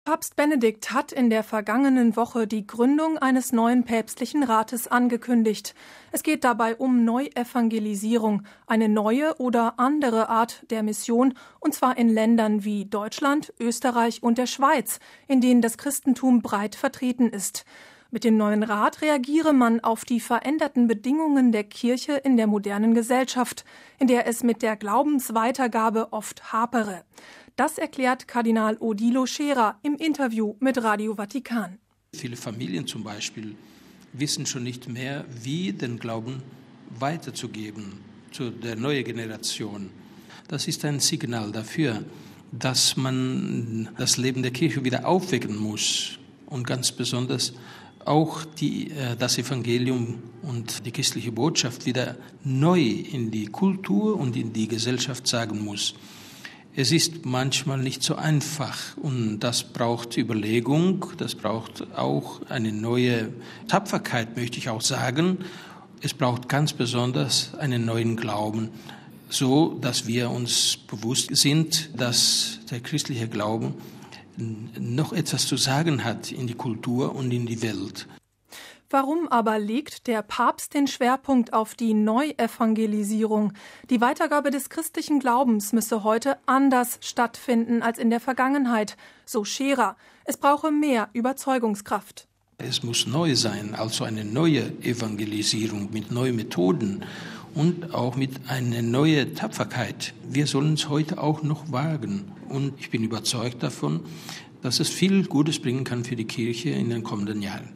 Das erklärt Kardinal Odilo Scherer im Interview mit Radio Vatikan: